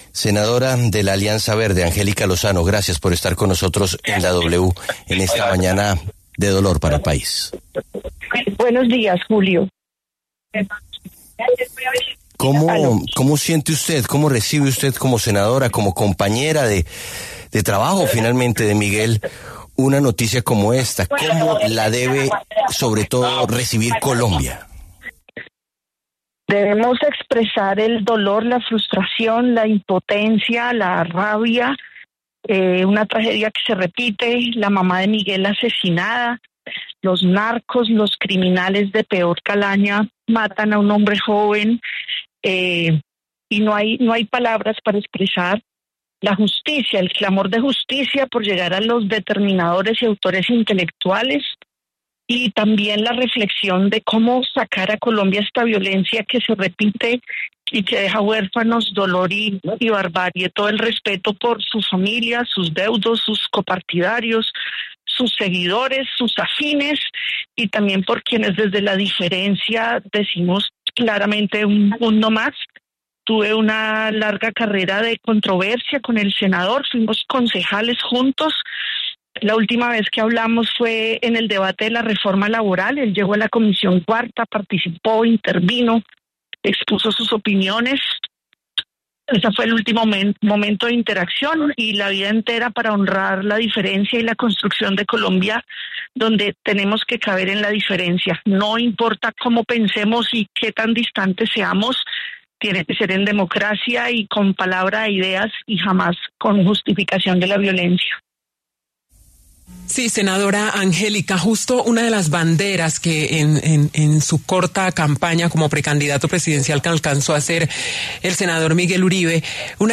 La senadora Angélica Lozano pasó por los micrófonos de La W para hablar sobre el magnicidio contra Miguel Uribe.